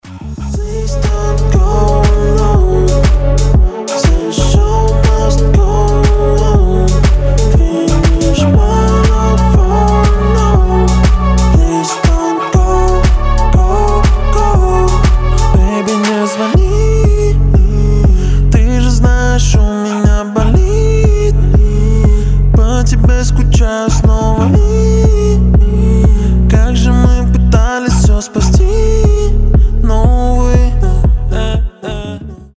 • Качество: 320, Stereo
атмосферные
кайфовые